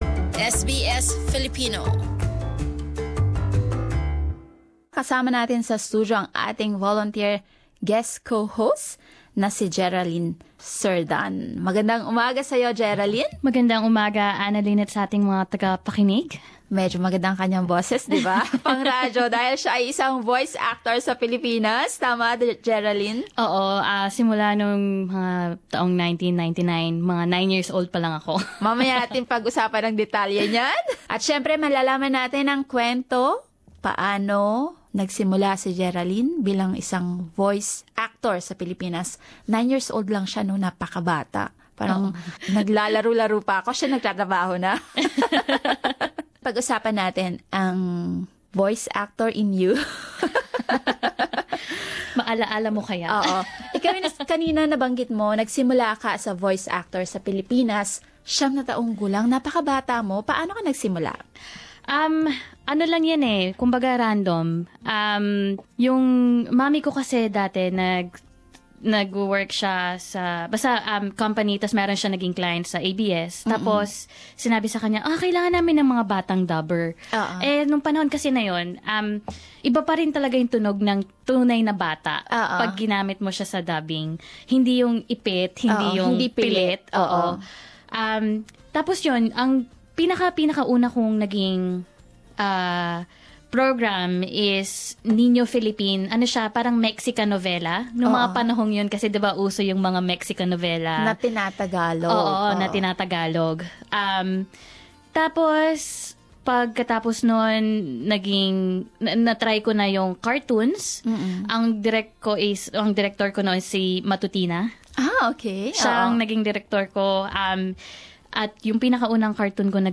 As our guest co-host, she shares some tips on enhancing and making use of one's voice talent. We also listen to some voice characters she did before.